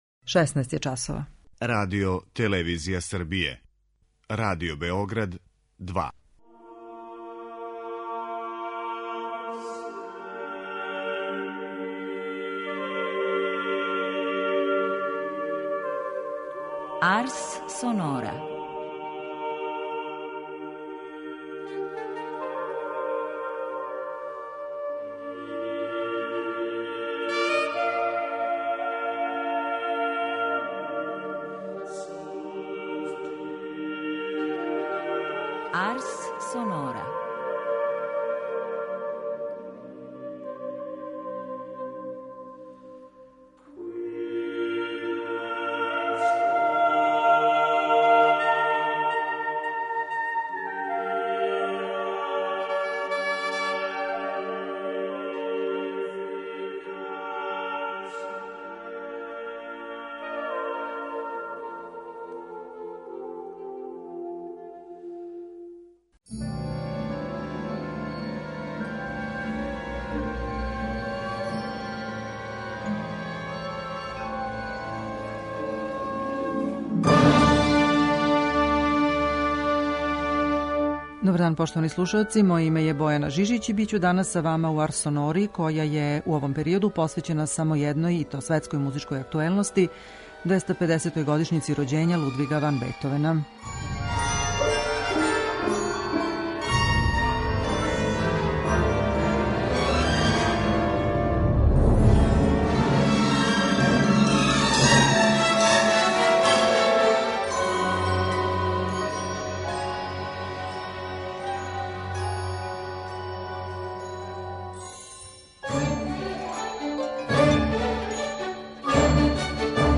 Слушамо како његове клавирске сонате свира пијаниста Игор Левит, који ће овога пута извести сонату опус 53 у Це-дуру, Валдштајн, опус 54 у Еф-дуру и опус 81а у Ес-дуру, Les Adieux.